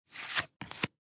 descargar sonido mp3 tarjeta